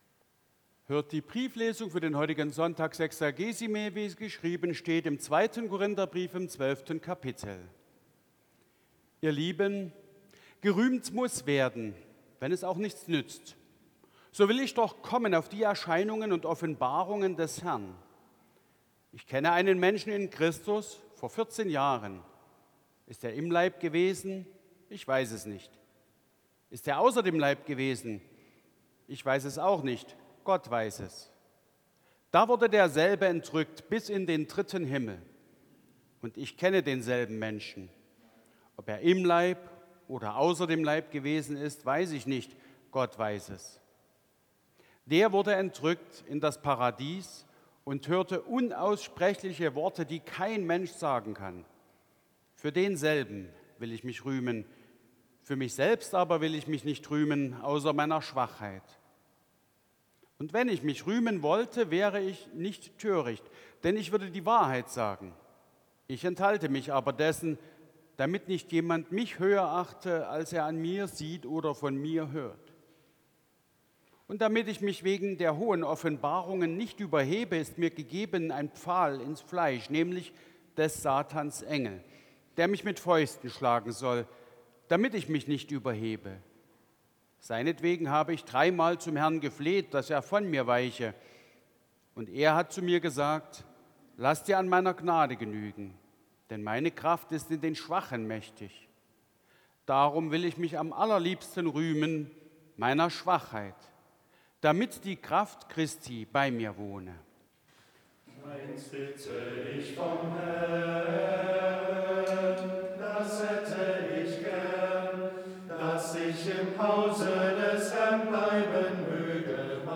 Gottesdienst am 23.02.2025
Lesung aus 2.Korinther 12,1-9 Ev.-Luth.